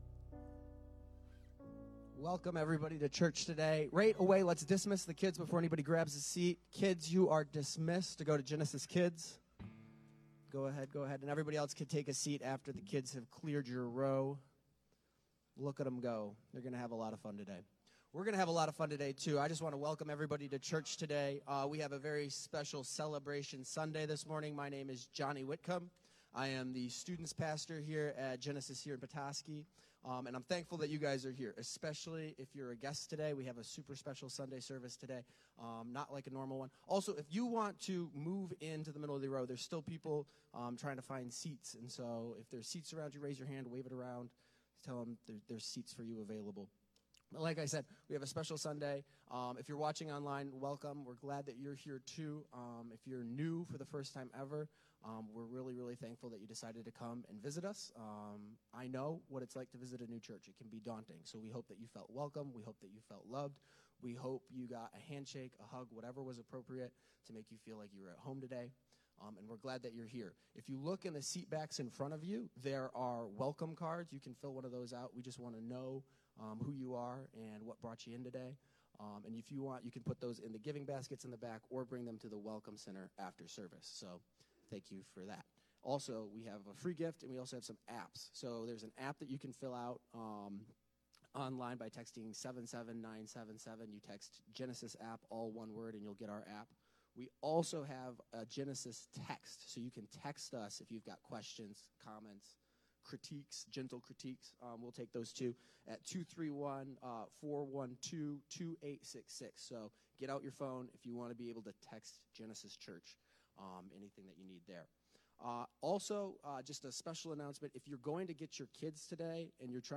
Celebration Sunday Service Type: Sunday Morning Preacher